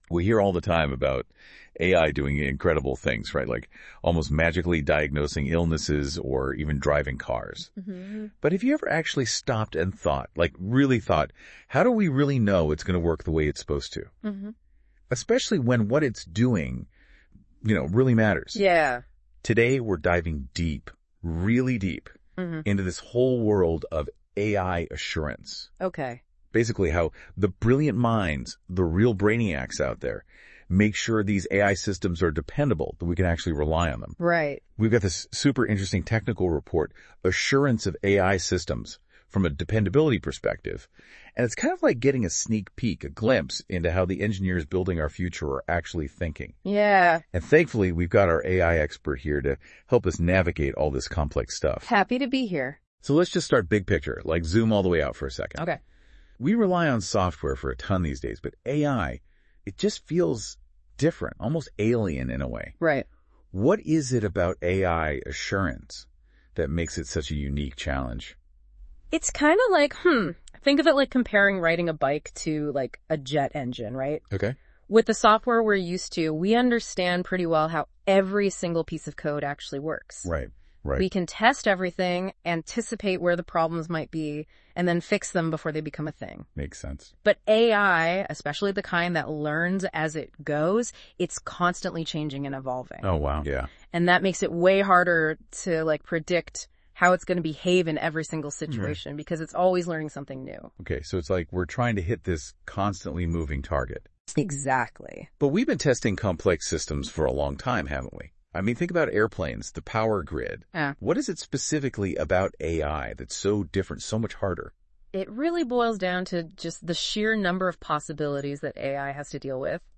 Podcast auto-generated from the pdf report by Google NotebookLM.